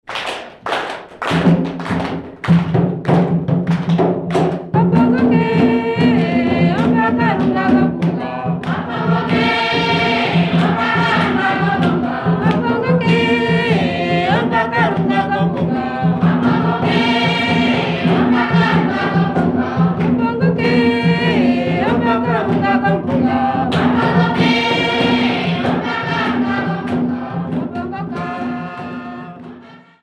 Bunya music workshop participants
Folk music
Sacred music
Field recordings
Africa Namibia Rundu sx
Kwangali Sanctus accompanied by drums and clapping
7.5 inch reel